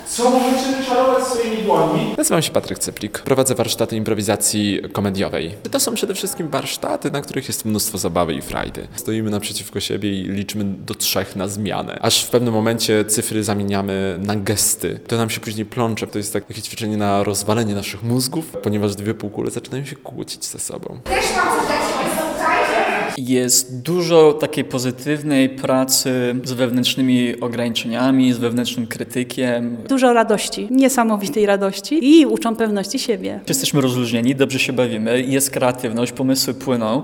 Ćwiczenia improwizacji komediowej dla początkujących.